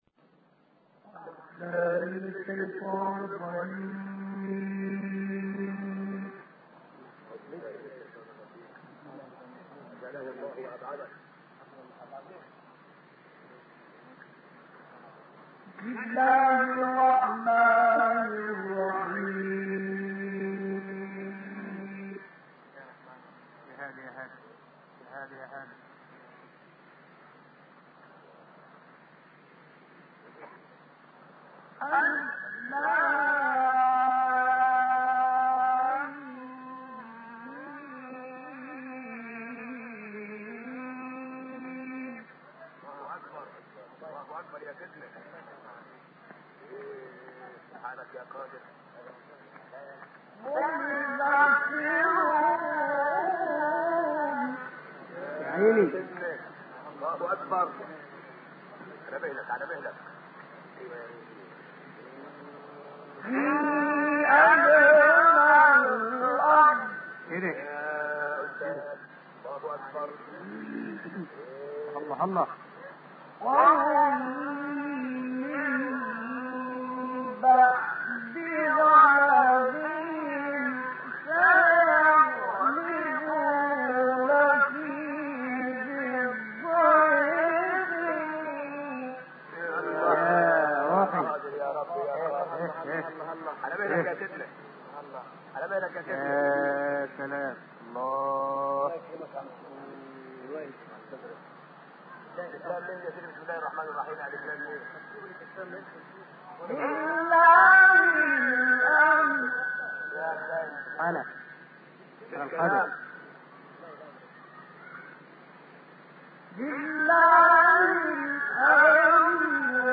این تلاوت که در شهر اسکندریه مصر اجرا شده مدت زمان آن 48 دقیقه است.